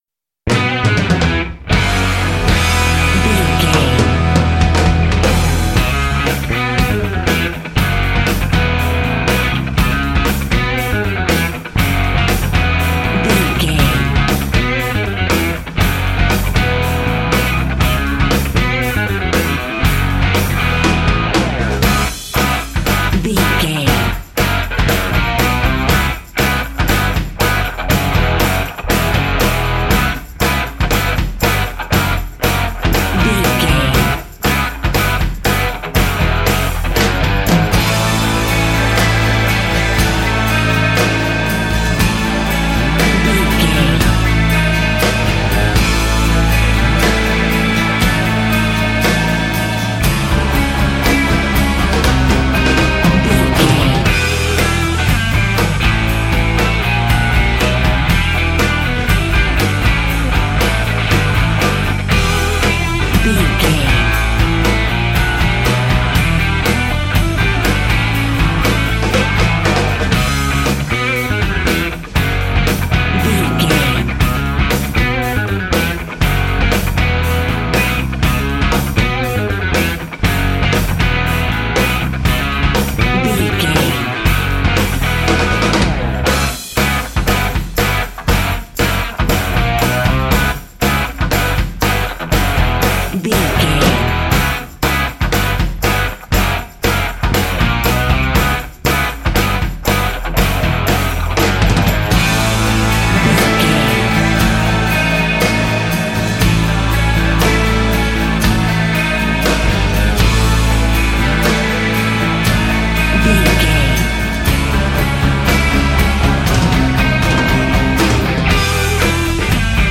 Ionian/Major
C#
drums
electric guitar
bass guitar
Sports Rock
pop rock
hard rock
lead guitar
aggressive
energetic
intense
powerful
nu metal
alternative metal